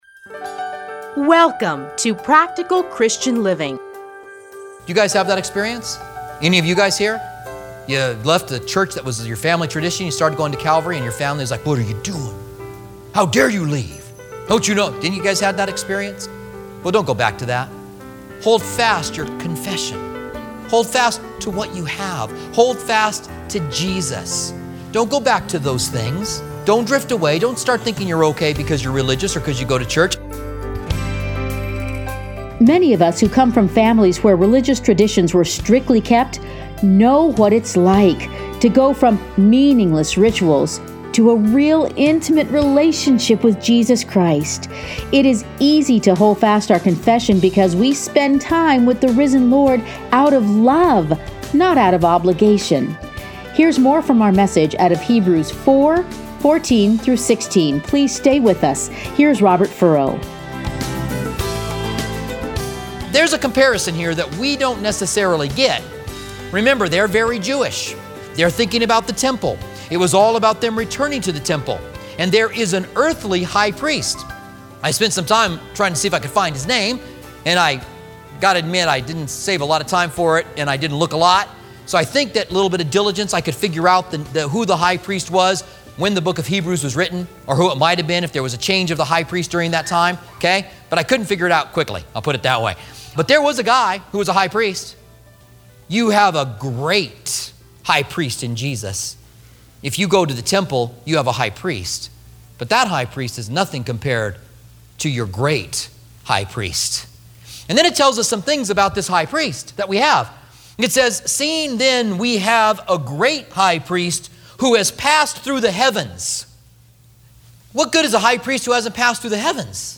Listen here to a teaching from Hebrews.